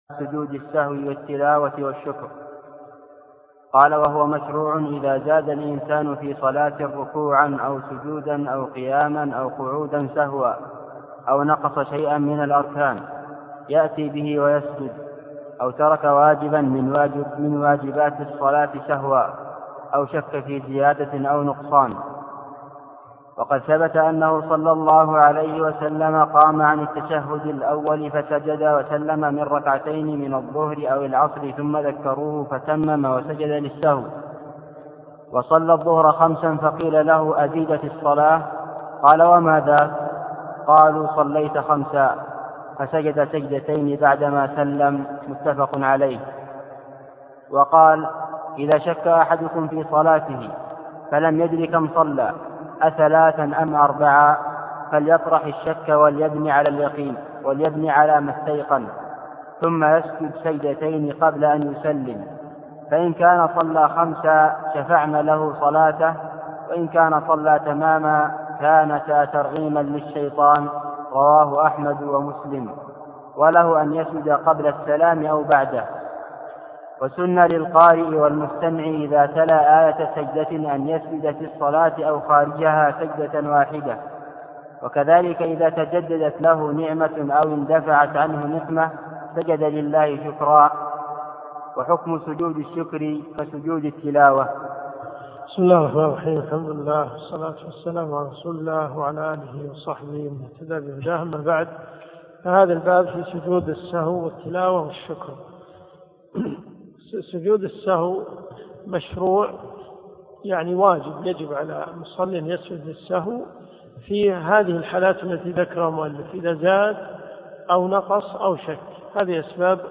الدروس الشرعية
منهج السالكين . كتاب الصلاة من ص 26 باب سجود السهو والتلاوة والشكر -إلى- ص 31 قوله ( ... فليصنع كما يصنع الامام ) . المدينة المنورة . جامع البلوي